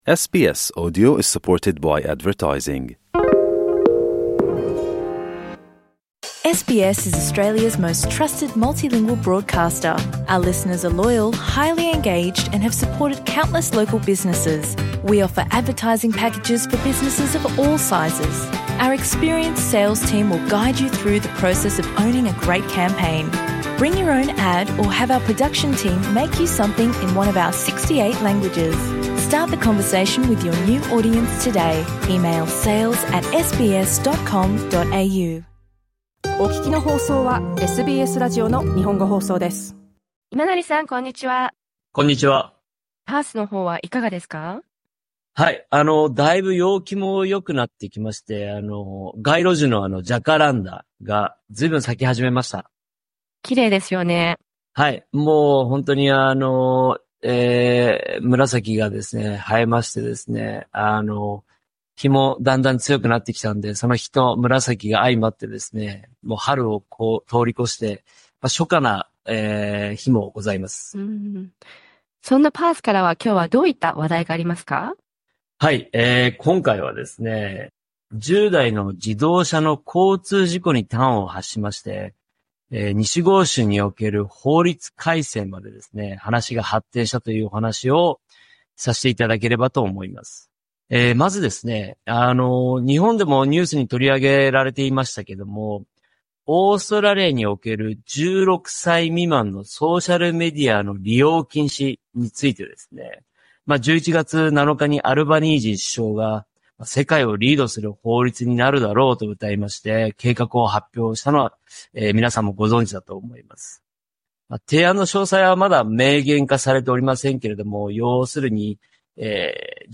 reports from Perth.